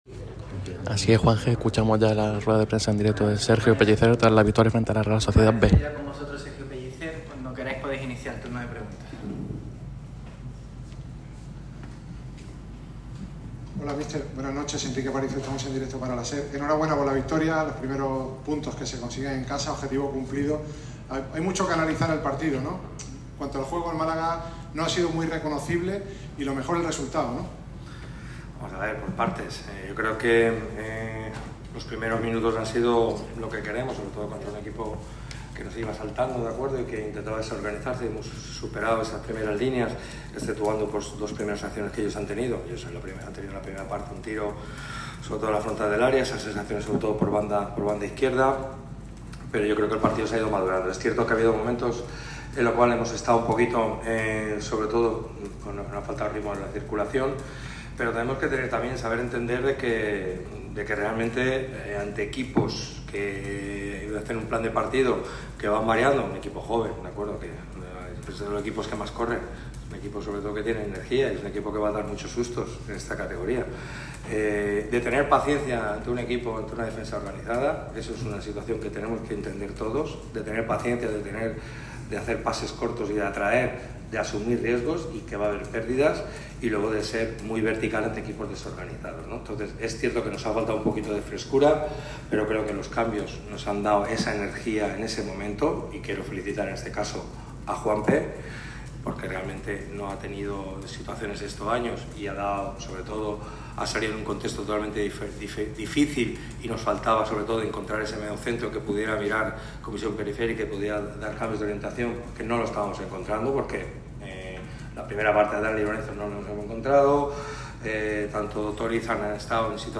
El entrenador del Málaga CF, Sergio Pellicer, ha atendido a los medios en sala de prensa después de la victoria del equipo ante la Real Sociedad B. El técnico de Nules ha repasado el triunfo y distintos nombres propios.